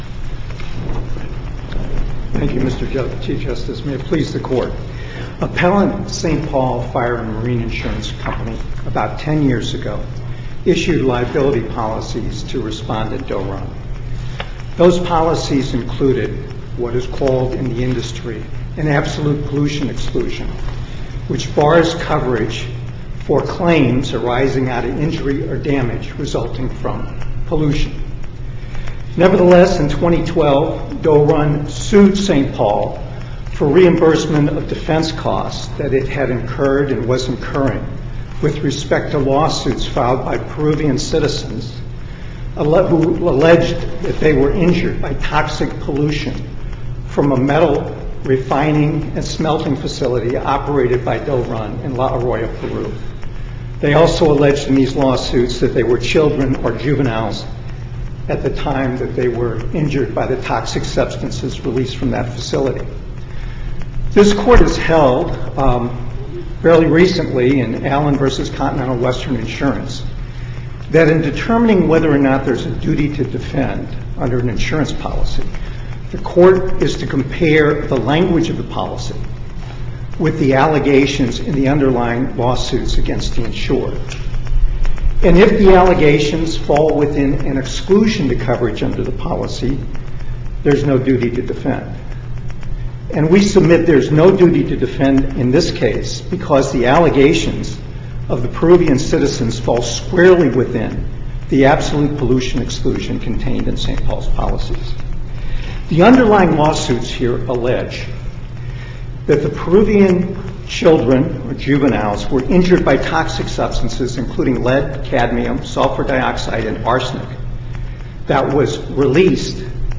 MP3 audio file of arguments in SC96107